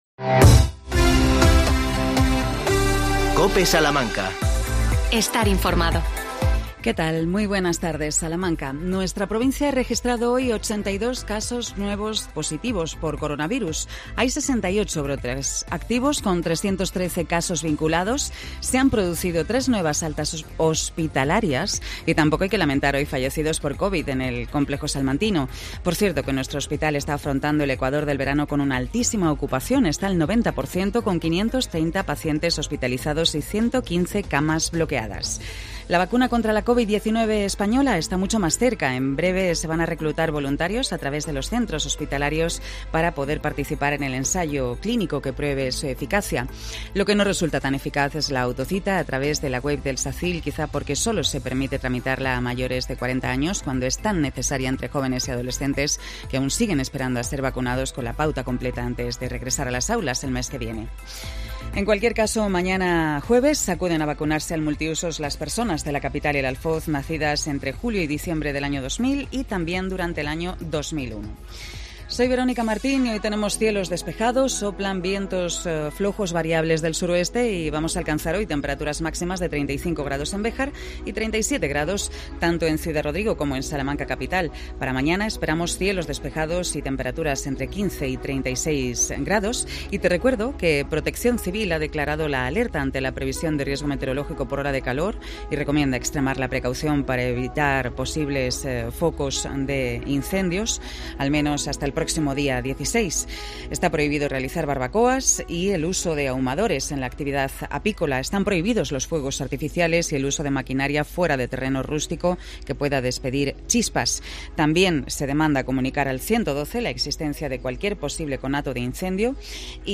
11 08 21 INFORMATIVO MEDIODIA COPE SALAMANCA